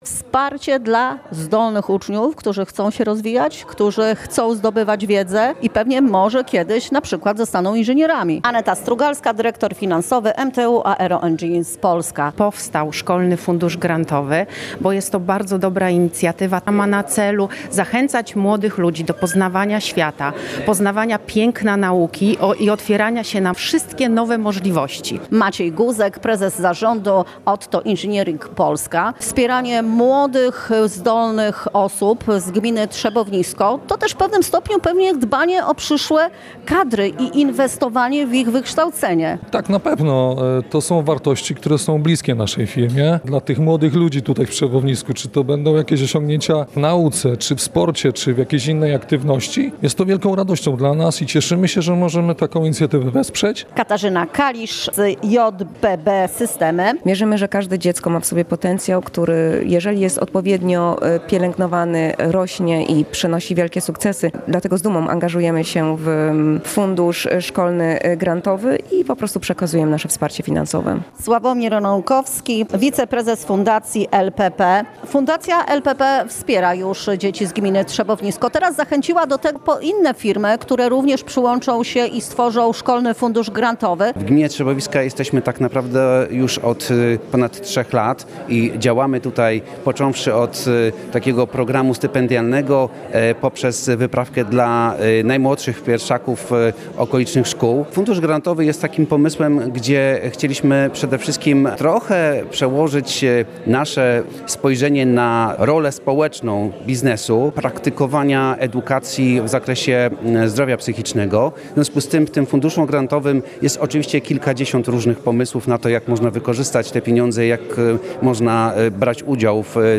Sławomir Porada wójt gminy Trzebownisko mówi, że takie wsparcie to ogromna szansa dla uczniów z tej podrzeszowskiej gminy.